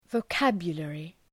{vəʋ’kæbjə,lerı}